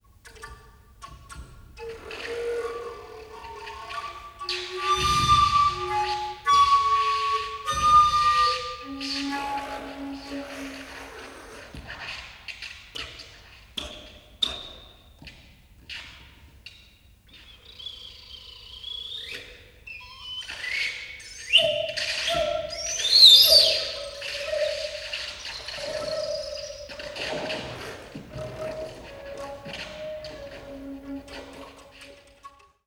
for renaissance tenor recorder and electronics
This piece is played using a real-time program on Max/MSP.